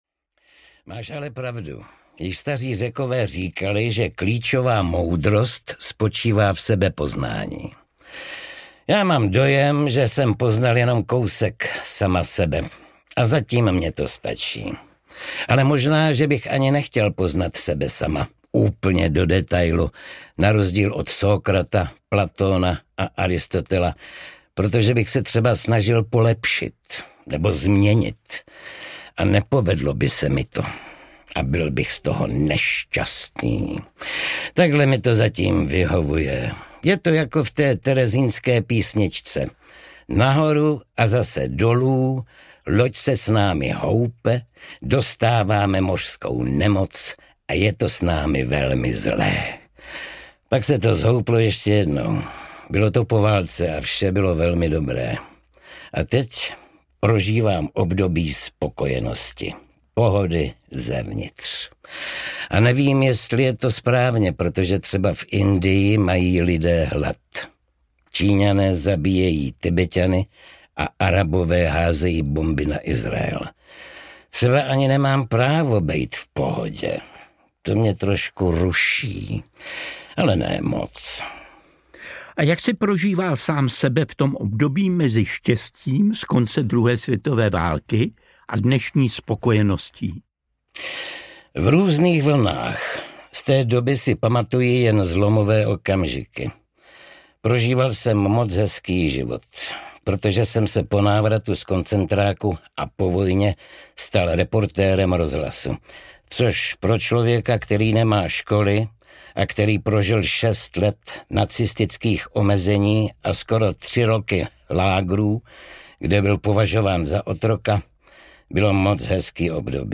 Tachles, Lustig audiokniha
Ukázka z knihy
Odpovědi na otázky Karla Hvížďaly tedy čte blízký Lustigův přítel Oldřich Kaiser.
• InterpretKarel Hvížďala, Oldřich Kaiser